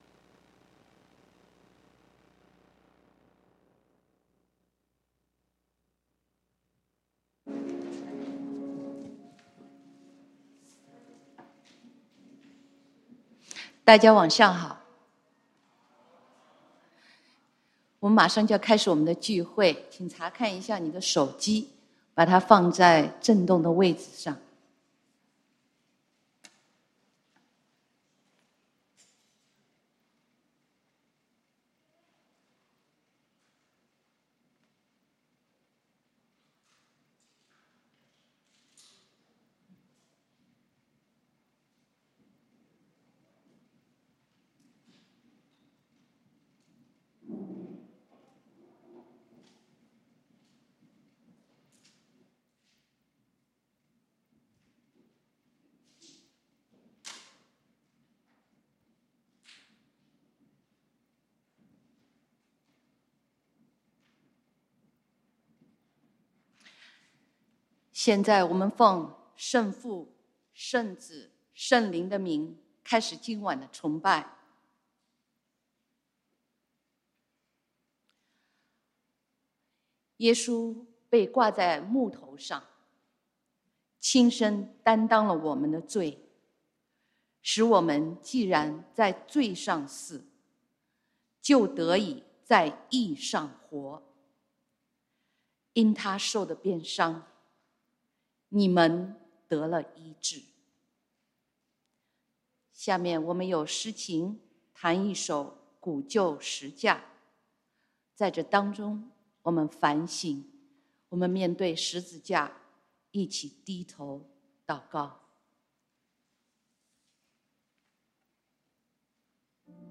受苦節崇拜：十架上的愛 (經文：羅馬書 5:8) | External Website | External Website